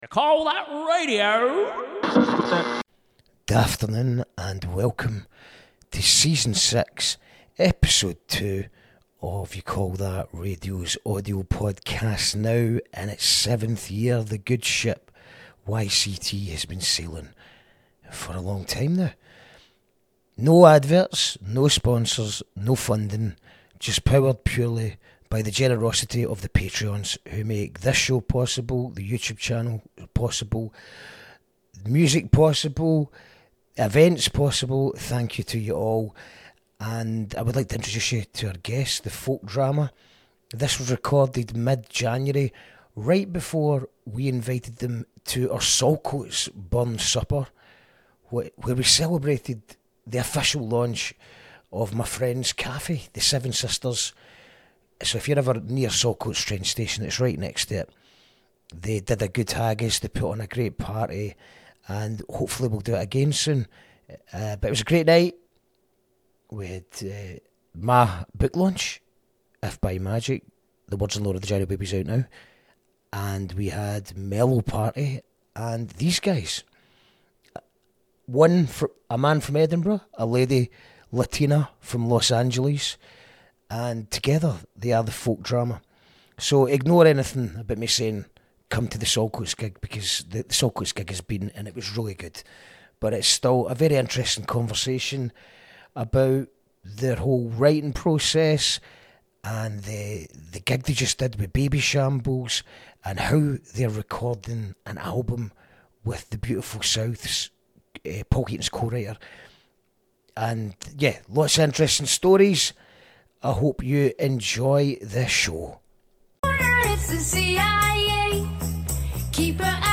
A conversation with alternative indie- folk duo 'The Folk Drama' with one latina from Los Angeles and the other from Edinburgh.